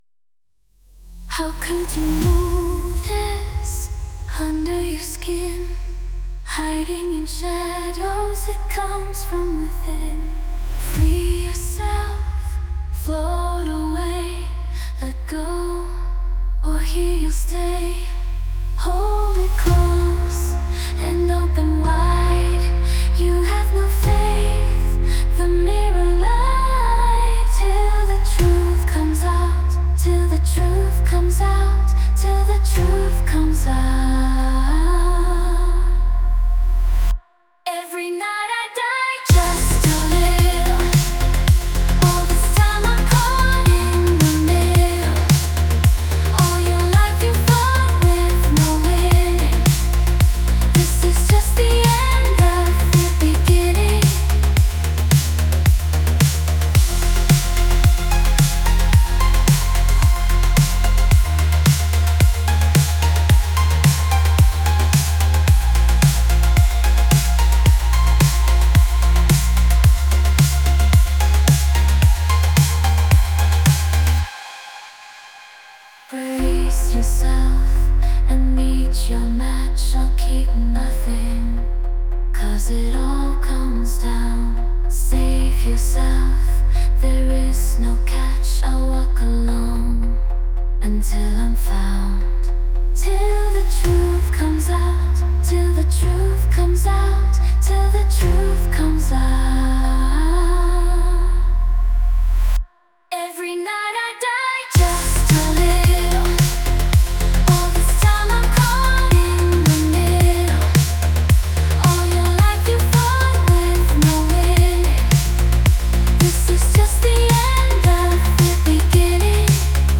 Female voice lyrics, Cyberpunk rhythms, syntwave, bass guitar, upbeat, action, orchestral, drum